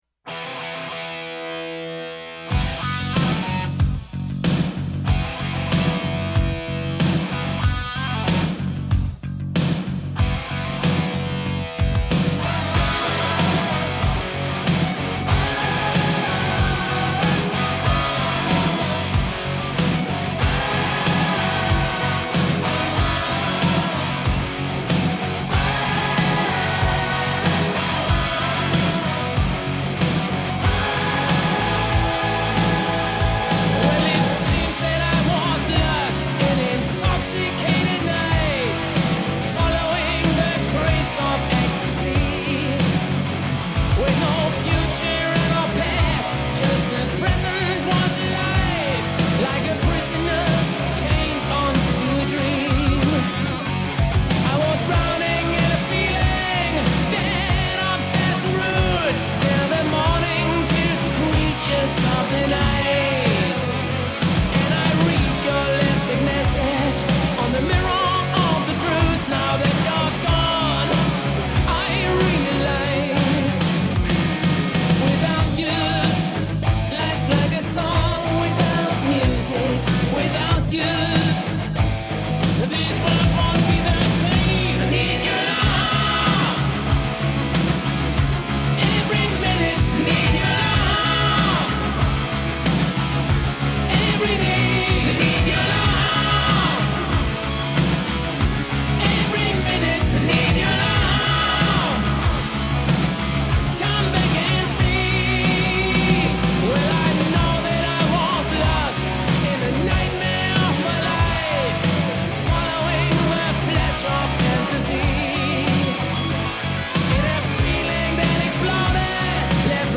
At first, I was a little dismayed by the production.